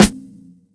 CL_SNR.wav